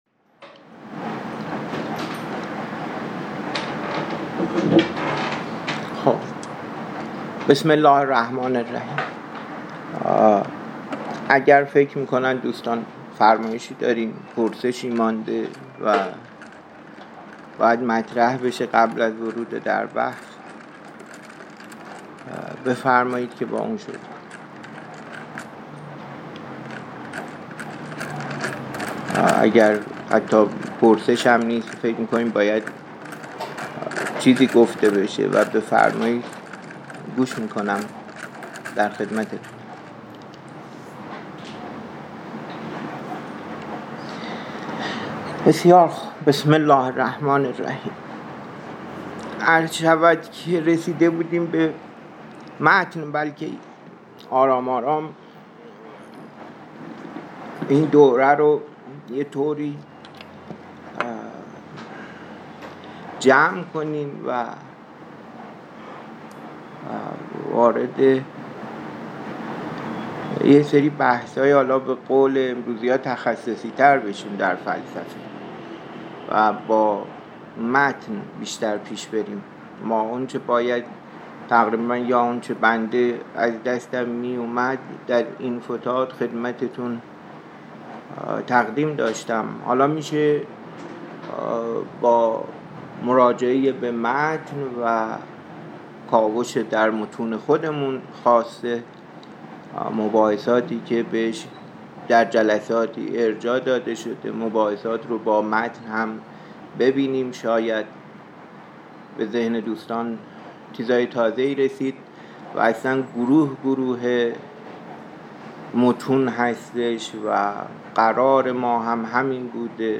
سخنرانی
در دبیرخانه شورای بررسی متون و کتب علوم انسانی (در پژوهشگاه علوم انسانی و مطالعات فرهنگی) برگزار می شود.